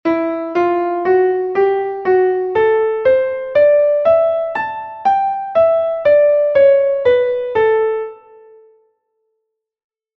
As escalas
Unha escala é a sucesión de notas ordenadas ascendente e descendentemente.
ejemploescala.mp3